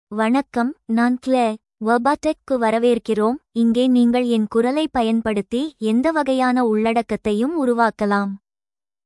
Claire — Female Tamil AI voice
Claire is a female AI voice for Tamil (India).
Voice sample
Female
Claire delivers clear pronunciation with authentic India Tamil intonation, making your content sound professionally produced.